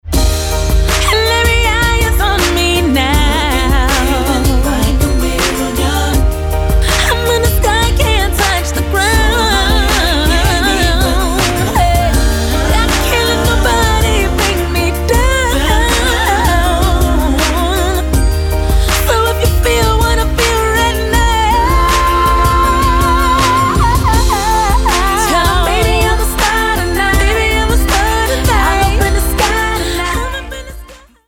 Americká R&B speváčka